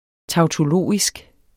tautologisk adjektiv Bøjning -, -e Udtale [ tɑwtoˈloˀisg ] Betydninger 1.